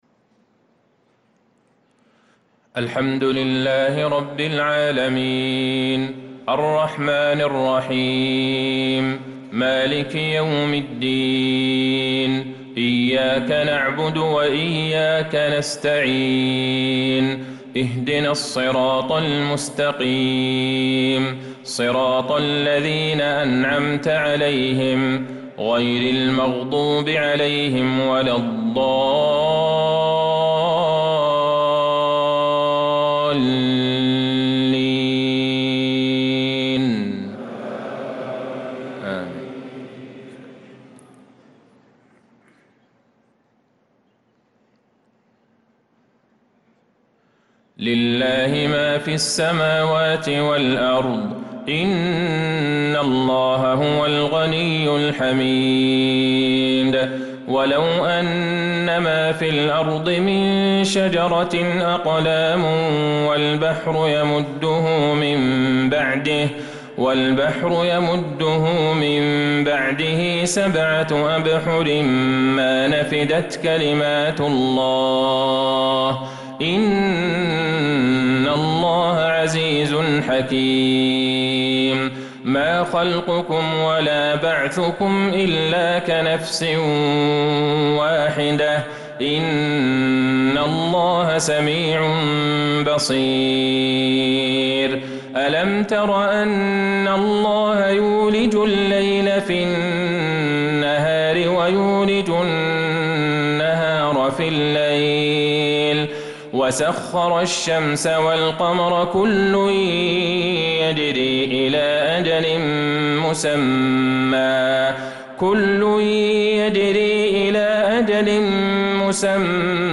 صلاة العشاء للقارئ عبدالله البعيجان 22 ذو القعدة 1445 هـ